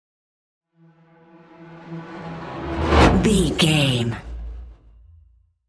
Whoosh electronic fast
Sound Effects
Atonal
Fast
futuristic
whoosh